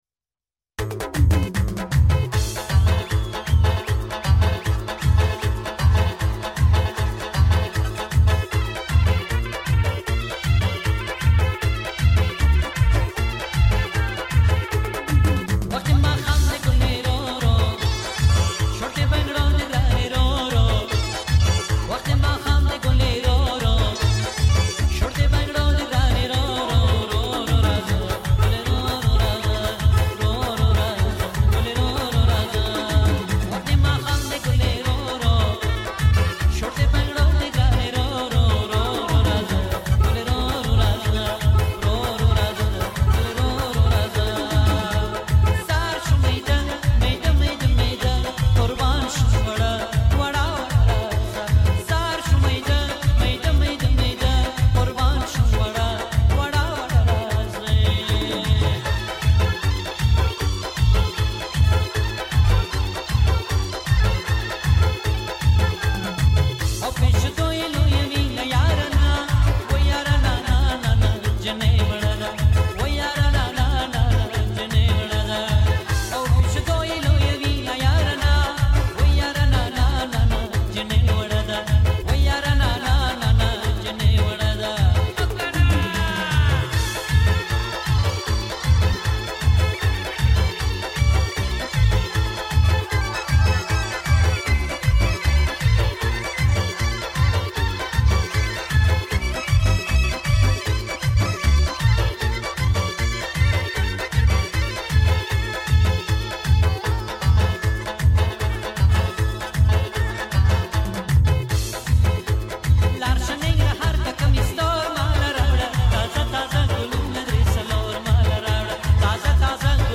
Pashto trending song